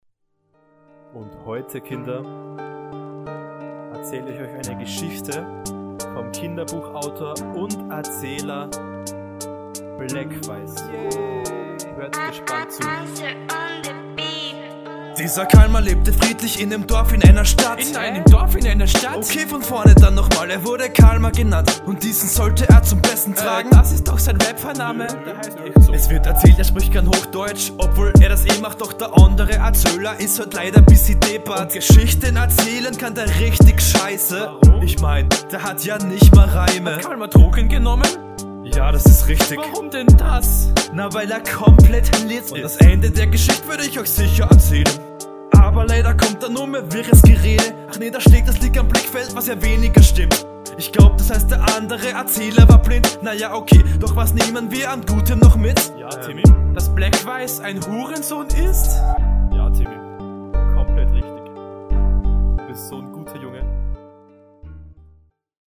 Intro ne Mischung aus Cringe und witzig :D Du kommst nice auf den Beat!